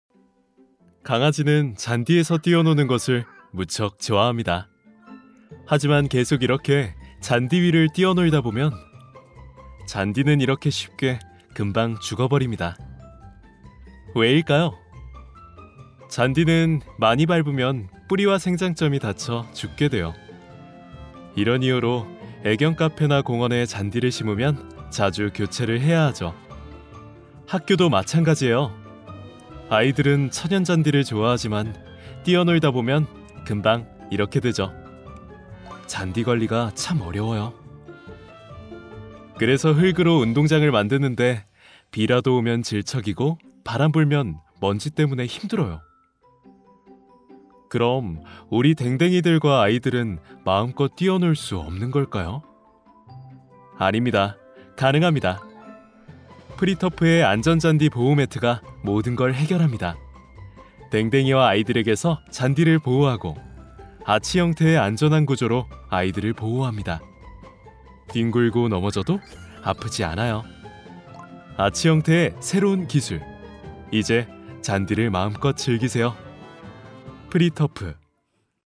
성우샘플
차분/편안